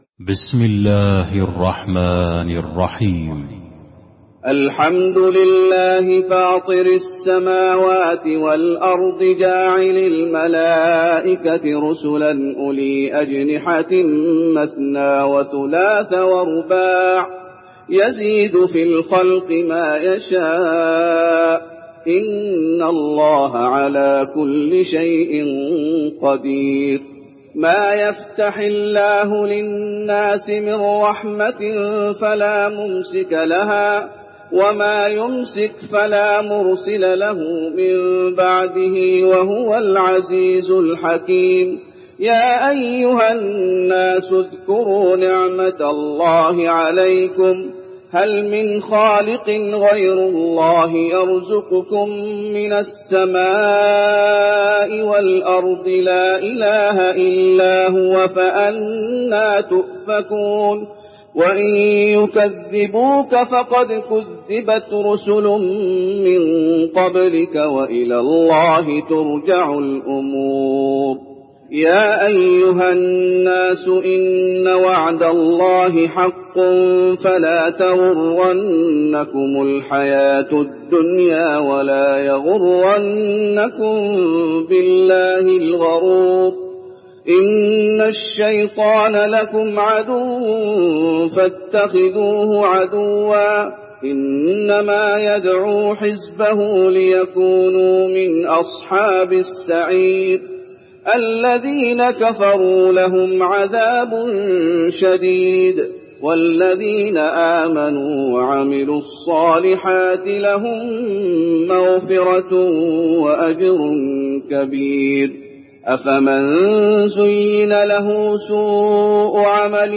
المكان: المسجد النبوي فاطر